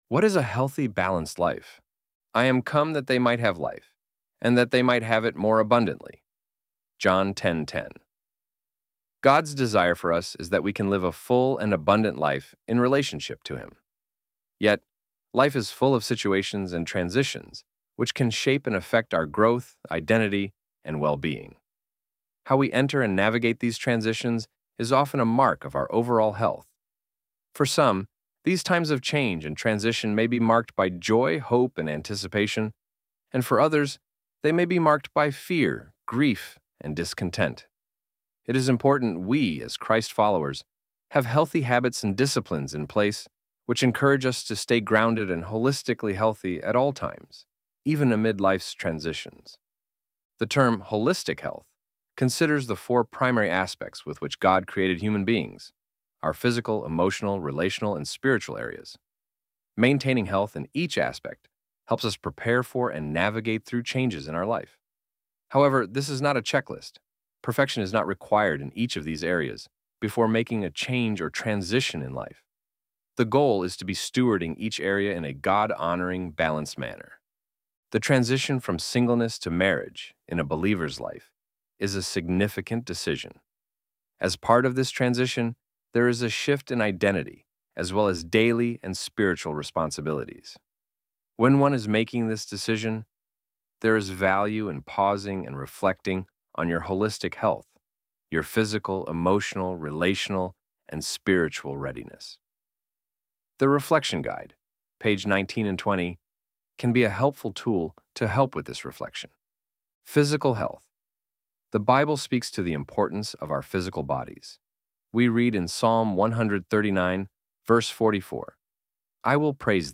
ElevenLabs_What_is_a_Healthy_Balanced_Life_-_CM.mp3